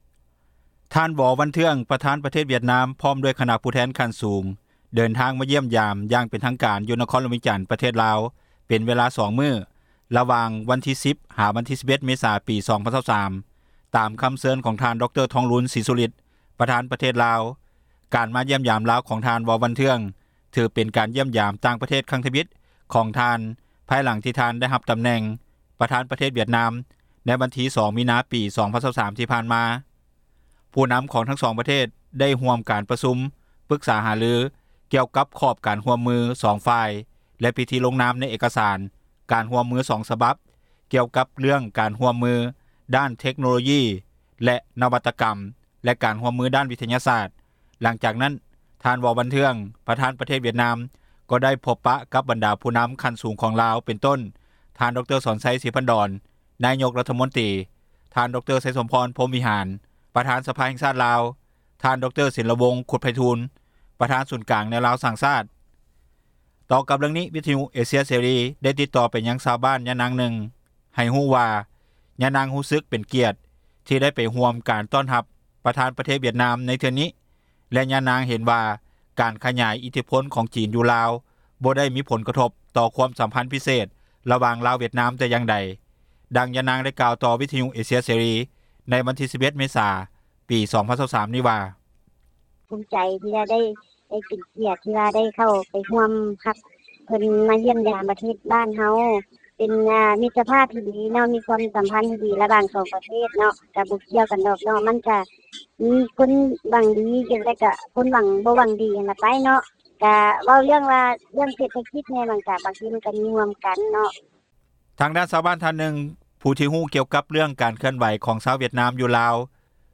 ຕໍ່ກັບເຣື່ອງນີ້, ວິທຍຸເອເຊັຽເສຣີ ໄດ້ຕິດຕໍ່ໄປຍັງ ຊາວບ້ານ ຍານາງນຶ່ງ ໃຫ້ຮູ້ວ່າ ຍານາງຮູ້ສຶກເປັນກຽດ ທີ່ໄດ້ໄປຮ່ວມ ການຕ້ອນຮັບ ປະທານປະເທດວຽດນາມ ໃນເທື່ອນີ້ ແລະຍານາງເຫັນວ່າ ການຂຍາຍ ອິດທິພົນຂອງຈີນ ຢູ່ລາວ ບໍ່ໄດ້ມີຜົລກະທົບຕໍ່ ຄວາມສຳພັນພິເສດ ລະວ່າງລາວ-ວຽດນາມ ແຕ່່ຢ່າງໃດ.